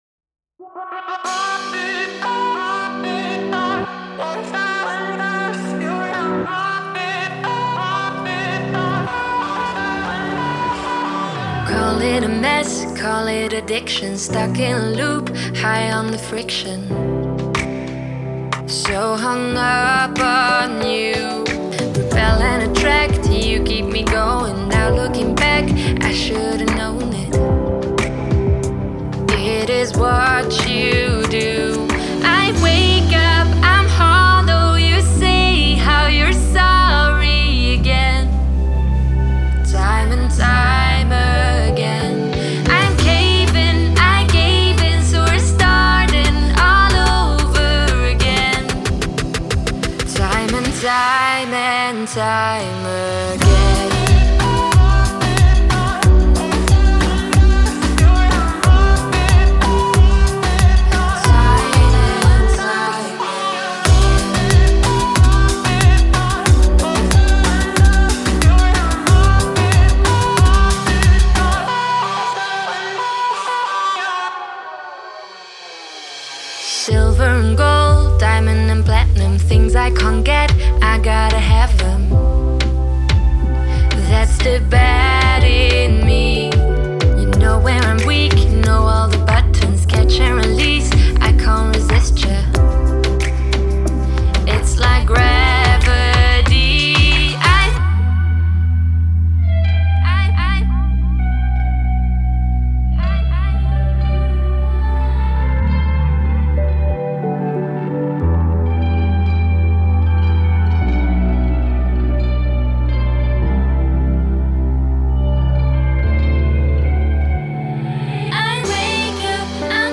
это яркая композиция в жанре поп-музыки